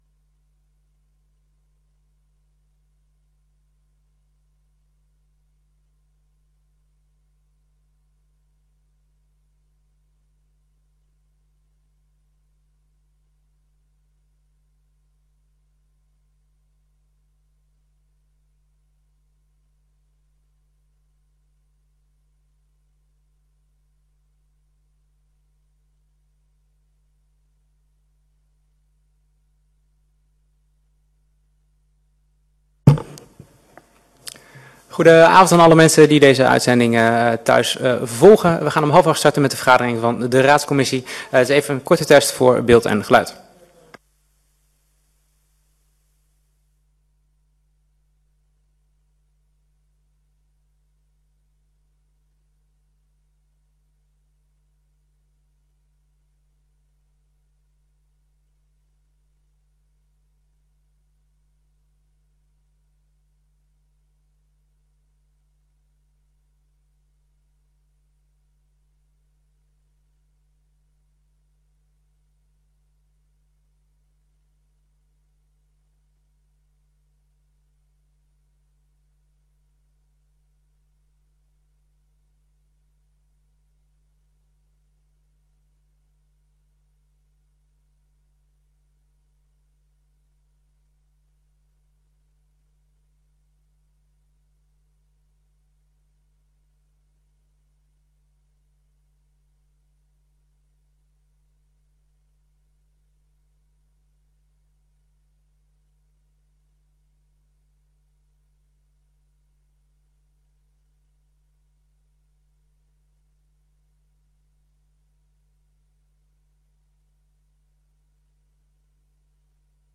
Download de volledige audio van deze vergadering
Locatie: Raadzaal Voorzitter: H.A. Zwakenberg